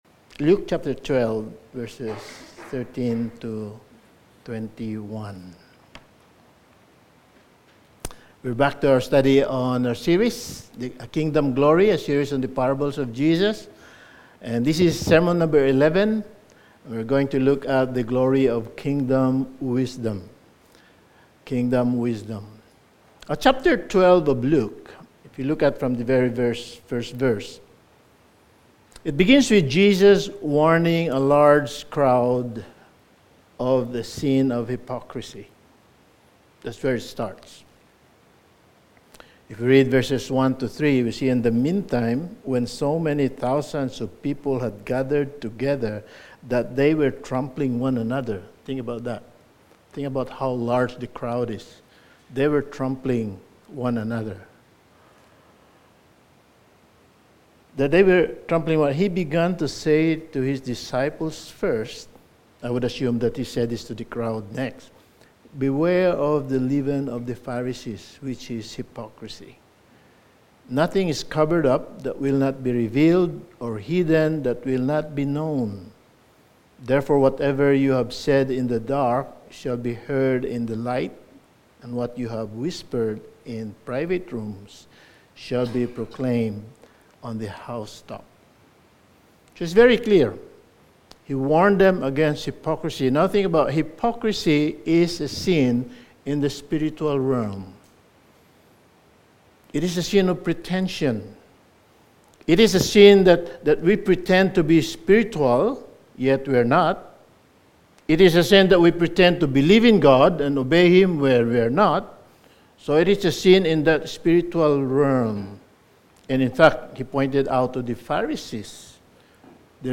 Sermon
Service Type: Sunday Evening